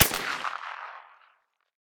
med_crack_07.ogg